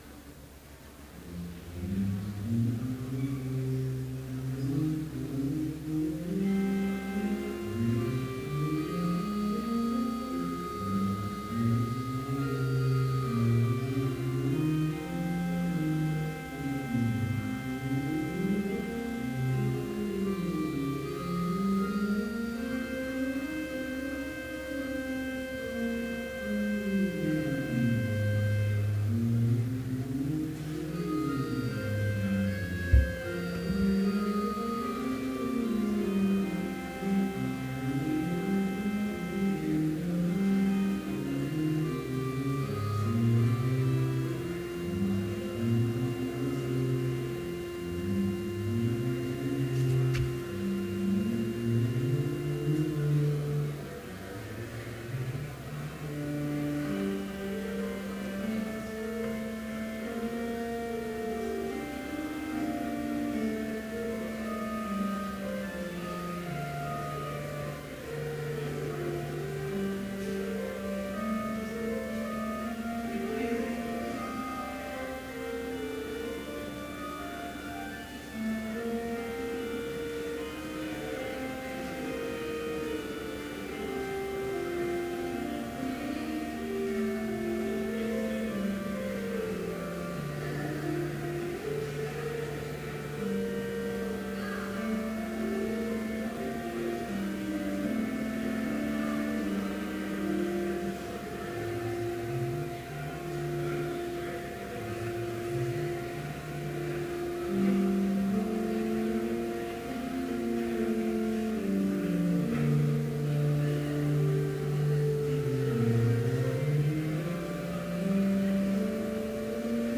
Complete service audio for Chapel - December 8, 2014